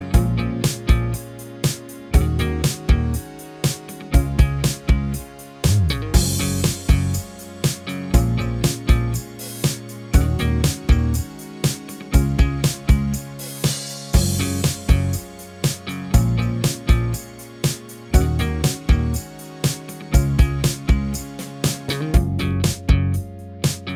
Minus Main Guitar Pop (2000s) 3:52 Buy £1.50